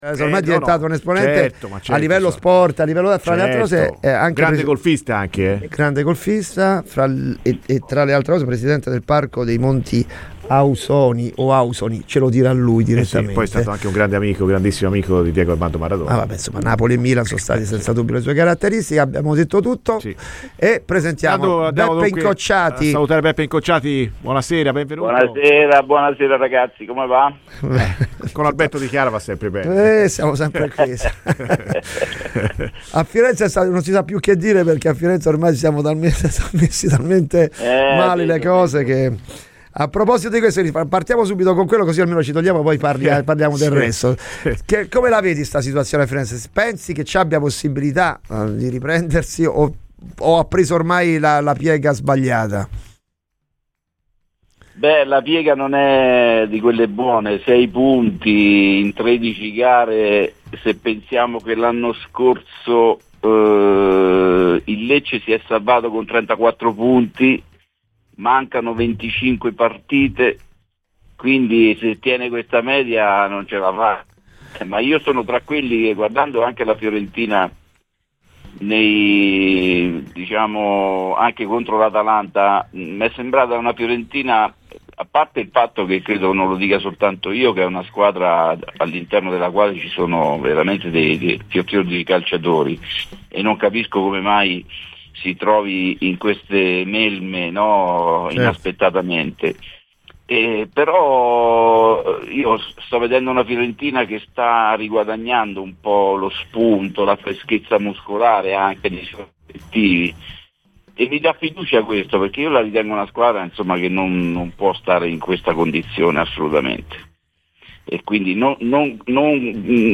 p>L'ex calciatore, ora allenatore, Giuseppe Incocciati è intervenuto a Radio FirenzeViola durante la trasmissione "Colpi d'Ala" con Alberto Di Chiara.